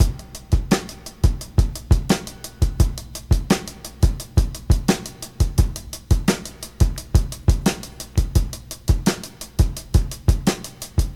86 Bpm Breakbeat Sample F# Key.wav
Free drum loop - kick tuned to the F# note. Loudest frequency: 1118Hz
86-bpm-breakbeat-sample-f-sharp-key-fIT.ogg